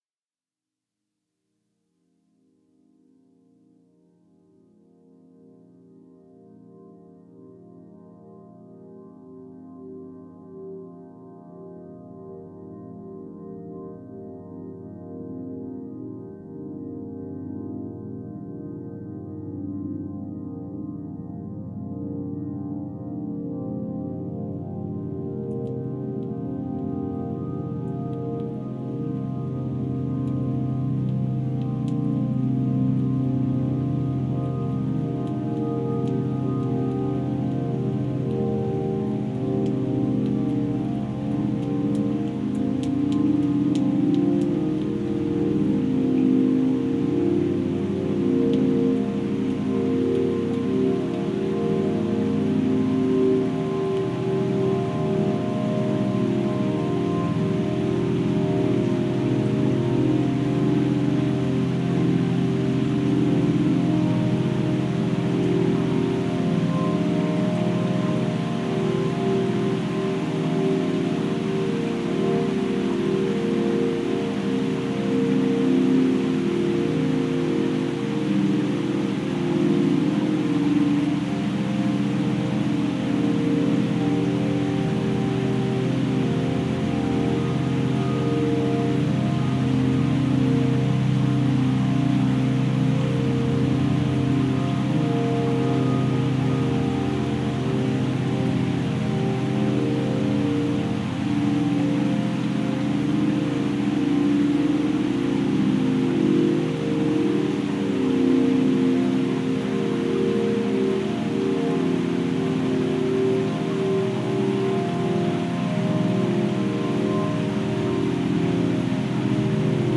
Genre: Ambient/Field Recording.